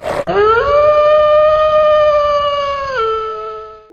howl.wav